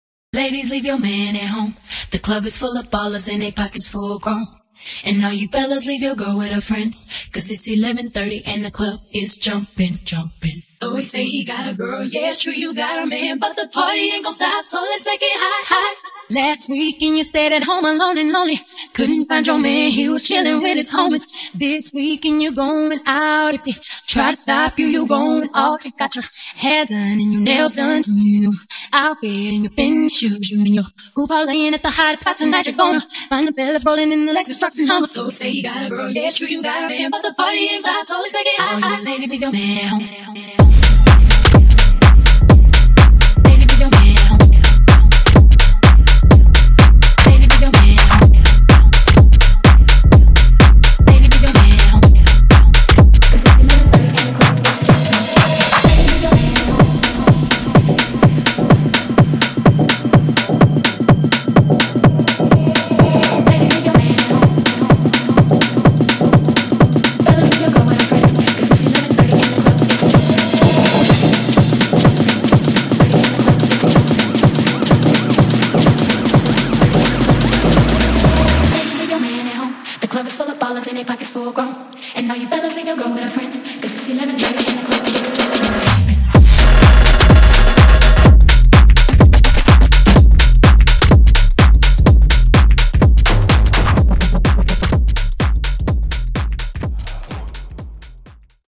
Acap Intro House)Date Added